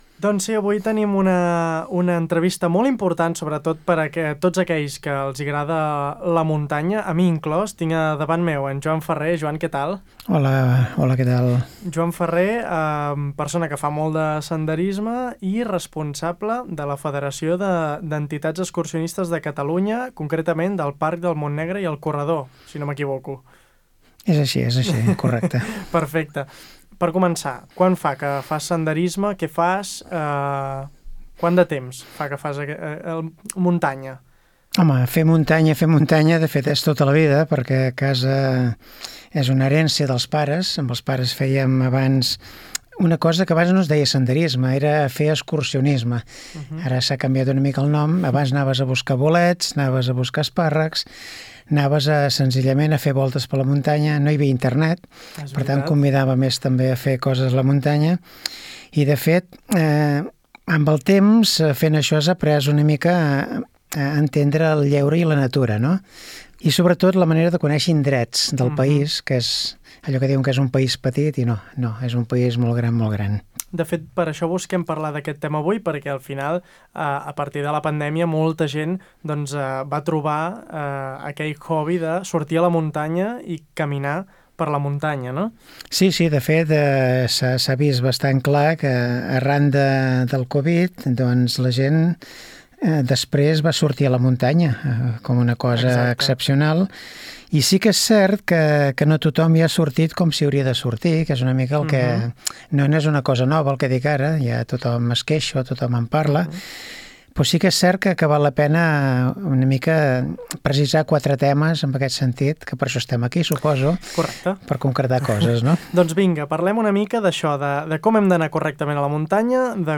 ENTREVISTA-BONA.mp3